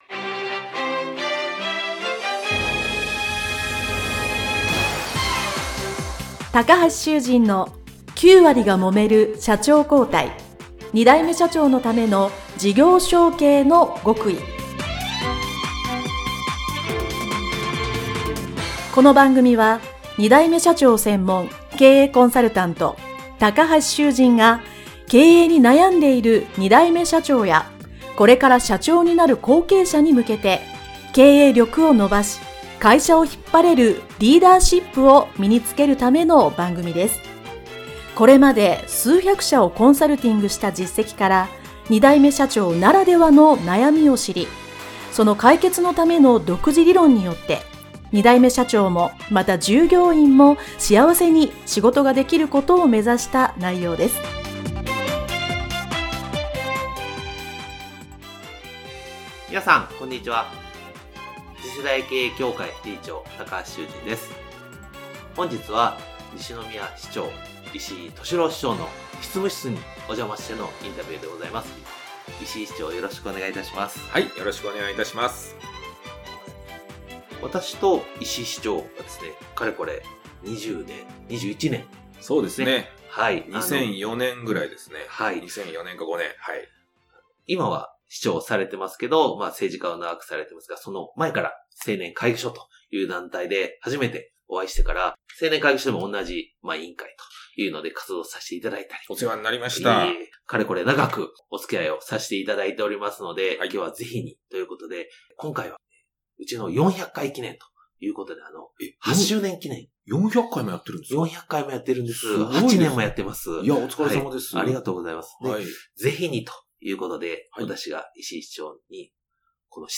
【9割がもめる社長交代】第401回 ＜特別対談＞西宮市長 石井としろう市長【インタビュー前編】